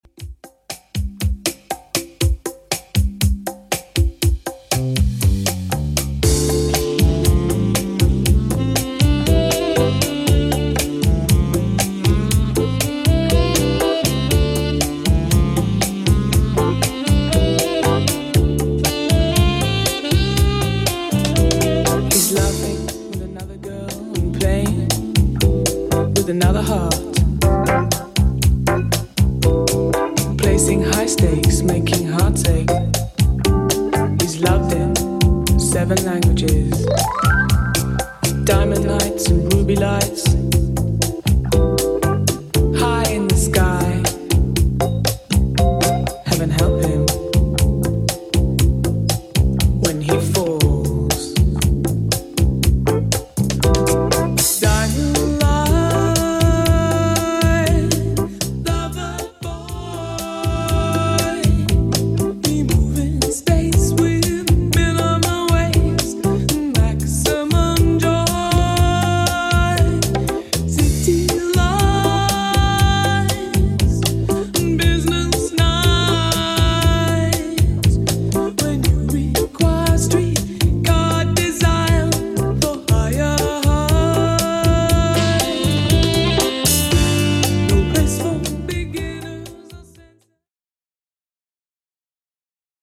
80s Redrum) 119bpm CLEAN